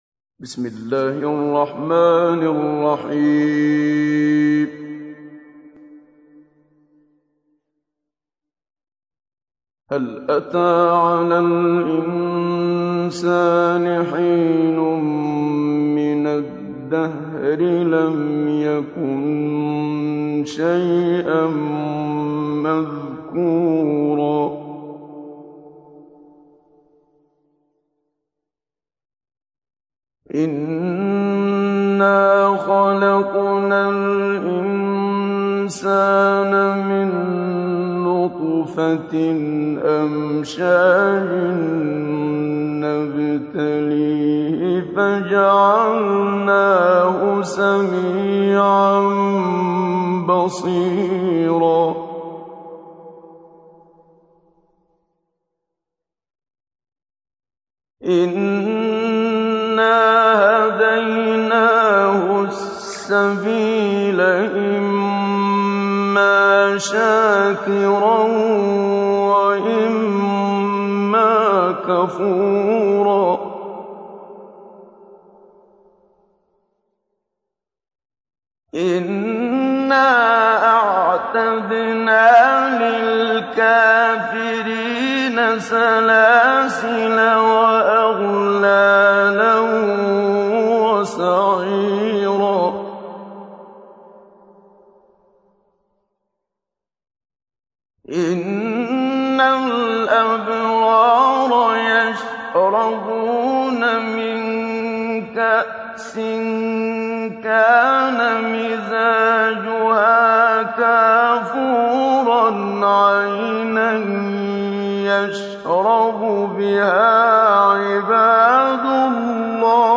تحميل : 76. سورة الإنسان / القارئ محمد صديق المنشاوي / القرآن الكريم / موقع يا حسين